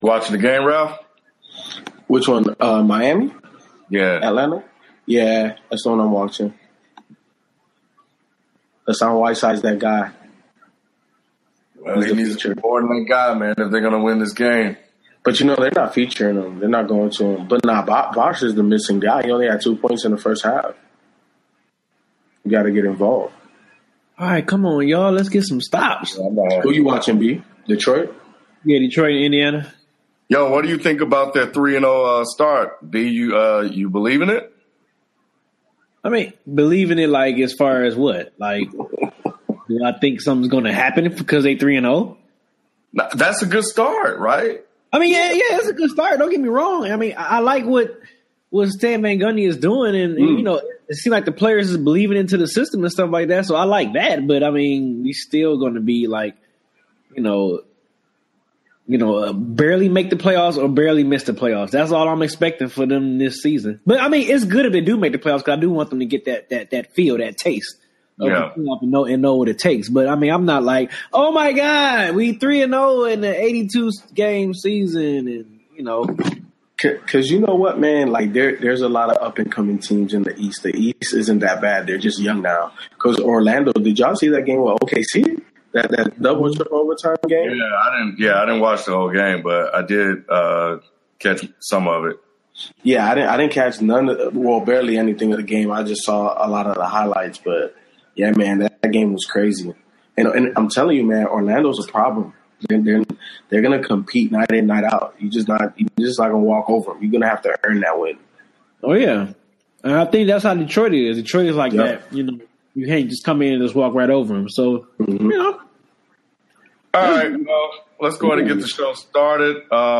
This is our first show on Blab and was shot live Tuesday at 9PM.